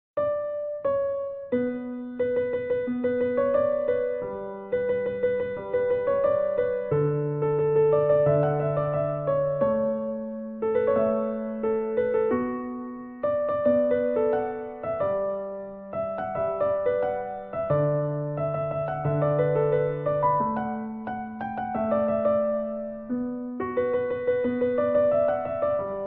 Tono de llamada Piano